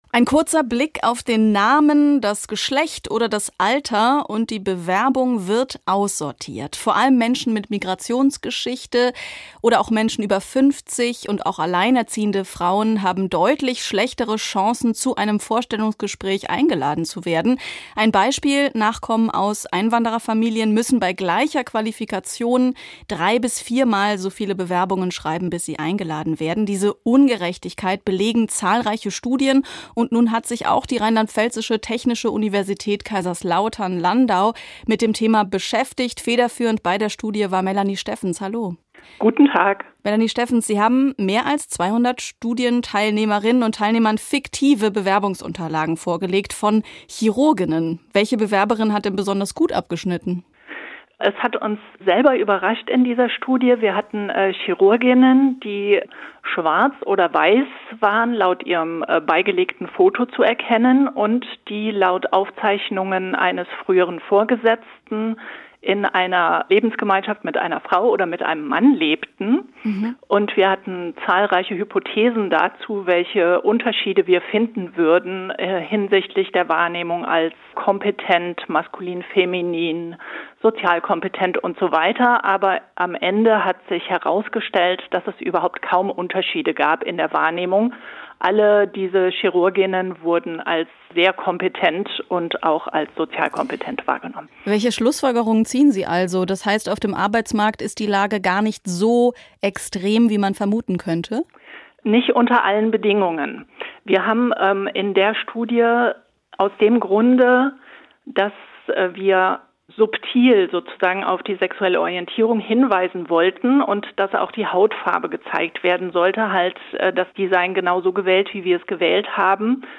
Interview mit